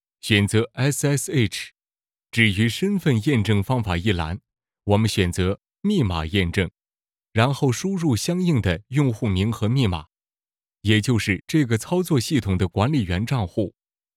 Chinese_Male_050VoiceArtist_4Hours_High_Quality_Voice_Dataset